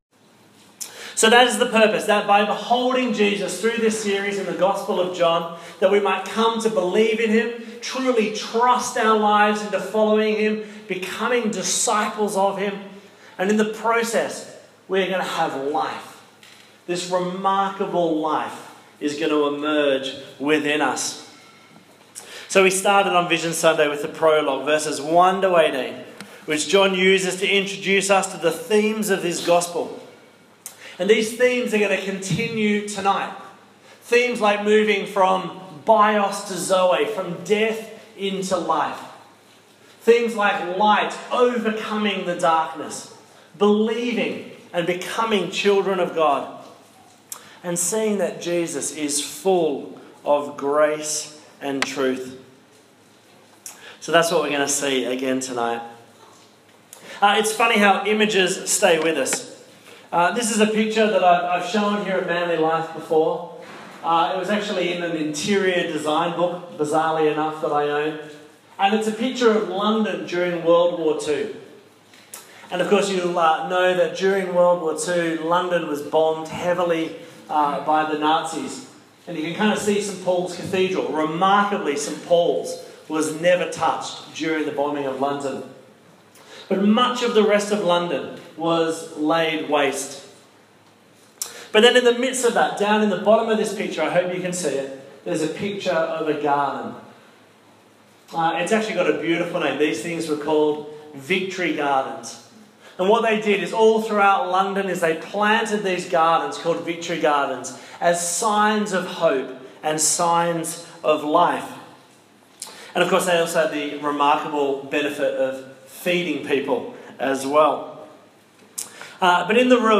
Apologies for the audio during the interview with Bono from U2 (it goes for about 2 minutes).